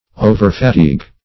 Overfatigue \O"ver*fa*tigue"\, n.
Overfatigue \O`ver*fa*tigue"\, v. t.